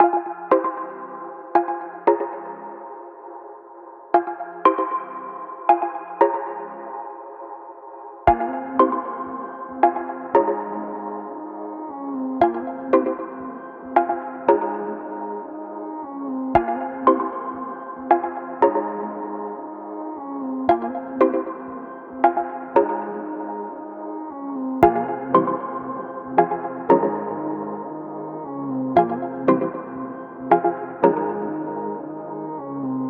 Teck-imagine_116bpm.wav